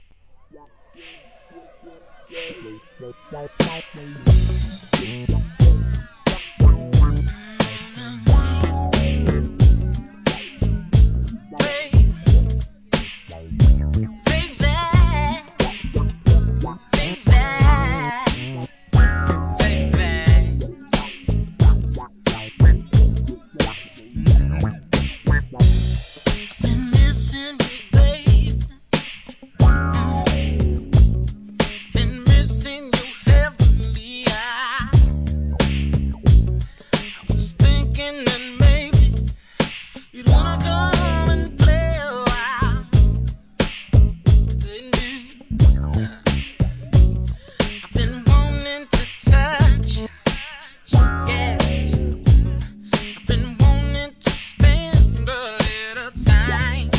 HIP HOP/R&B
ソウルフルなヴォーカル！